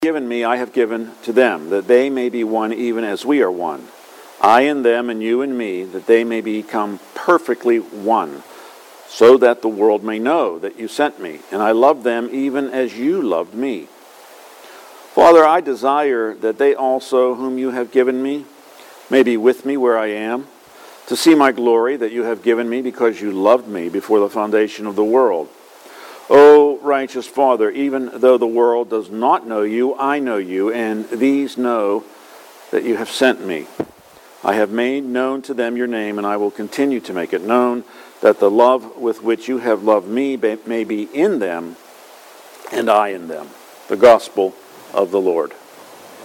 Sermon and Readings from Sunday, June 26.